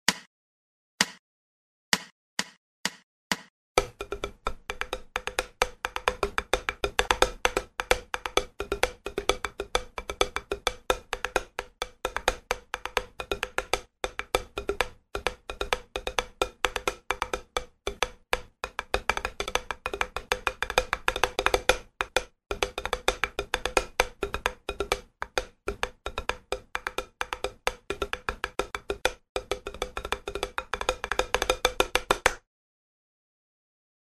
Все этюды записаны на педе для большей разборчивости и возможно помогут тем кто занимается по указанной книге самостоятельно.
Продолжим...Этюд №3, 4/4 темп 130